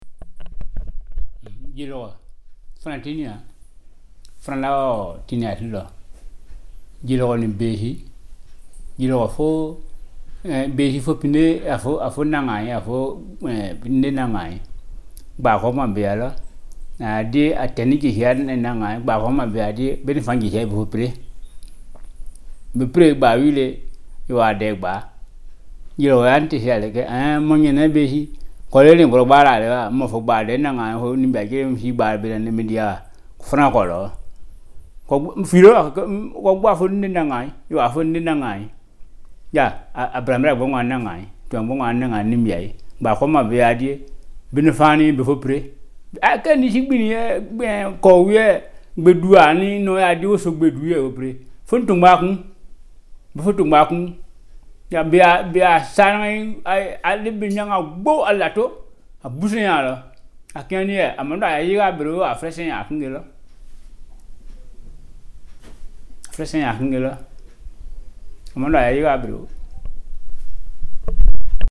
• Audiofiles were recorded in the field with a Tascam portable digital recorder.
• fieldwork in Bonosso village, Côte d’Ivoire, chiefly 2016-19.